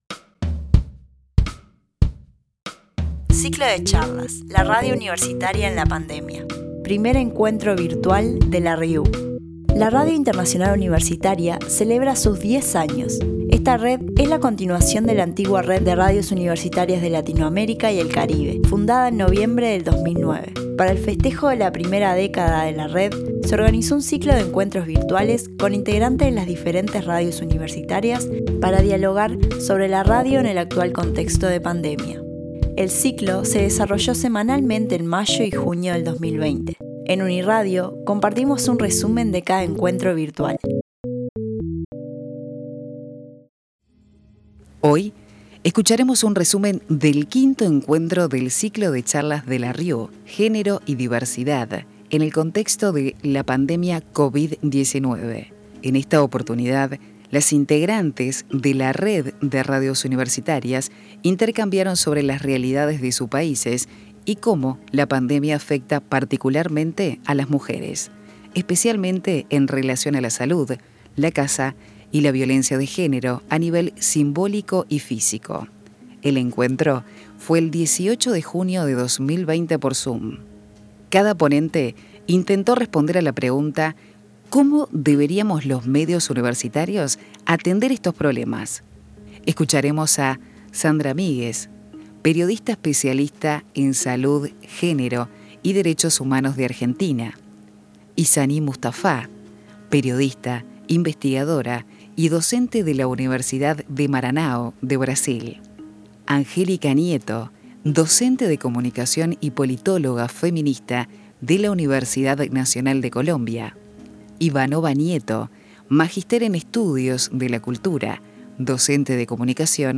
El encuentro fue el 18 de junio de 2020 por zoom. Cada ponente intentó responder a la pregunta ¿Cómo deberíamos los medios universitarios atender estos problemas?